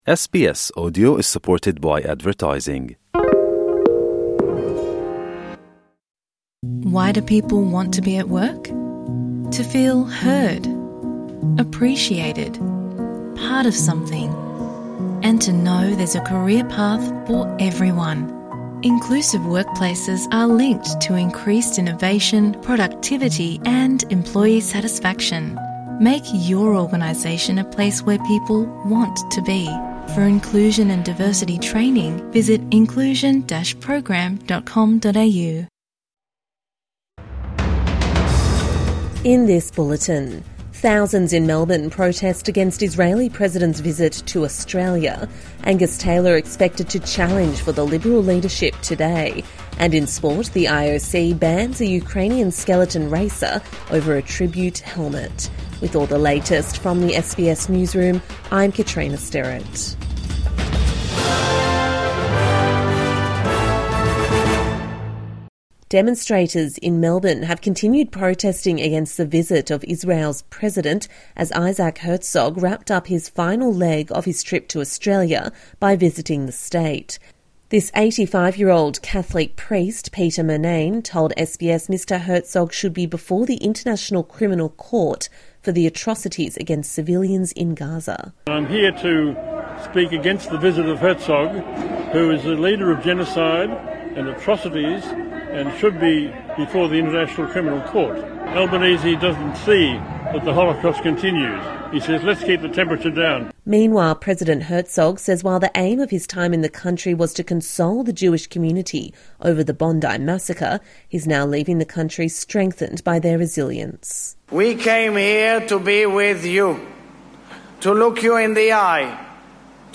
Further protests mark end of Israeli President’s Australia visit | Morning News Bulletin 13 February 2026